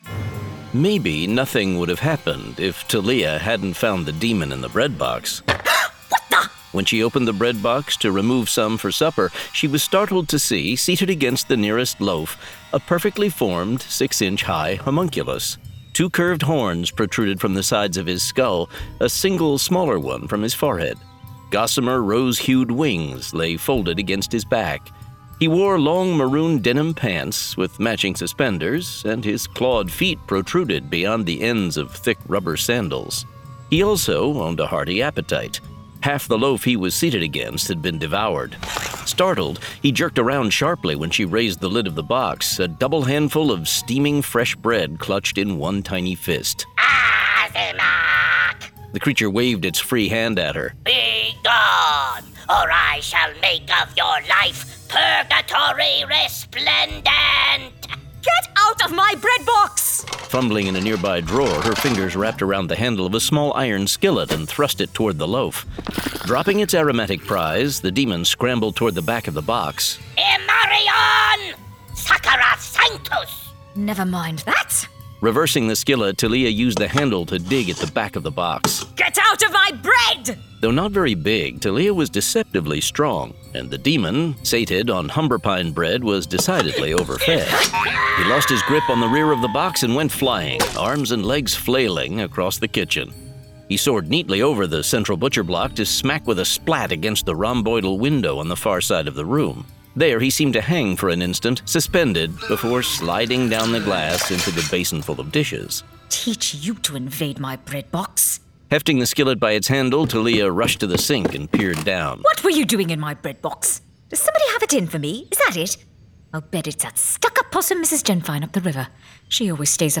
Spellsinger 7: Son of Spellsinger [Dramatized Adaptation]